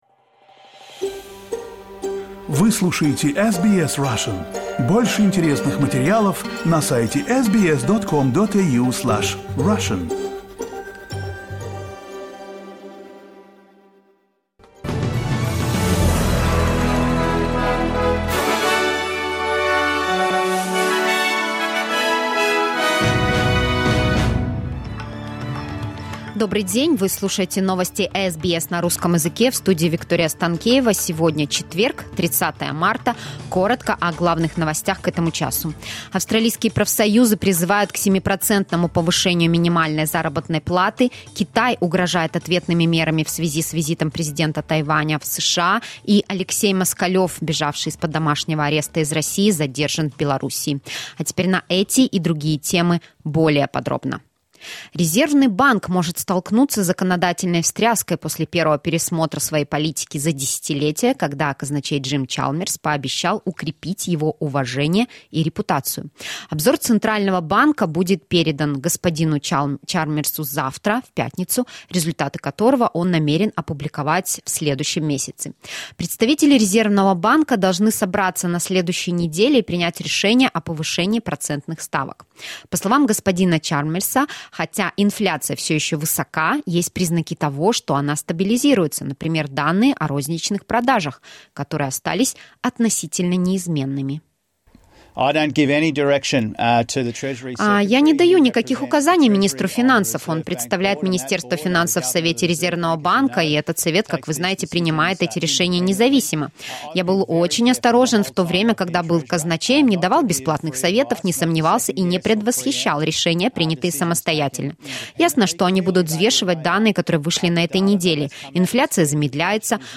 SBS news in Russian — 30.03.2023